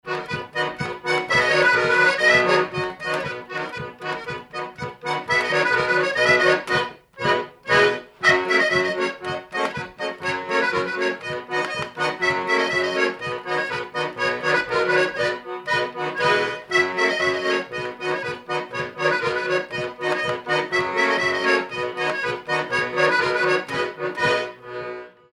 danse : monfarine, montfarine, montferrine, montferine
circonstance : bal, dancerie
Pièce musicale inédite